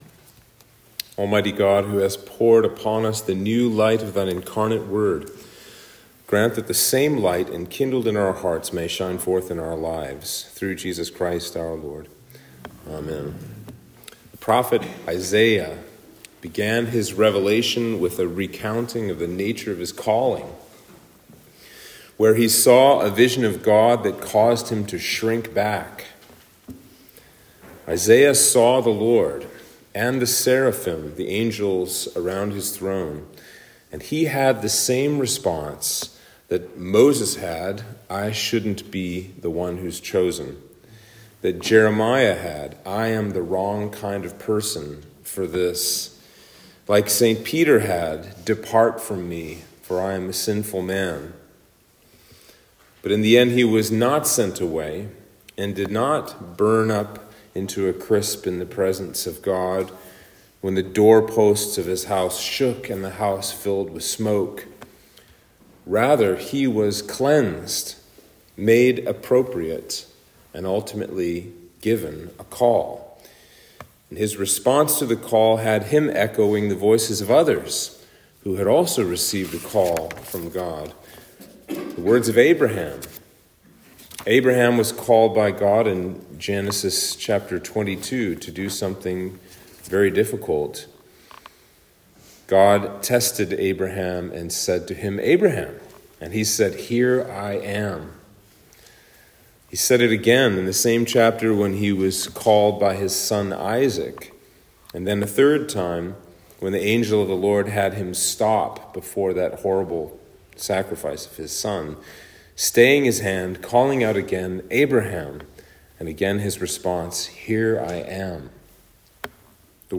Sermon for Christmas 2